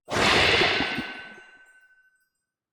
Minecraft Version Minecraft Version snapshot Latest Release | Latest Snapshot snapshot / assets / minecraft / sounds / mob / glow_squid / squirt1.ogg Compare With Compare With Latest Release | Latest Snapshot
squirt1.ogg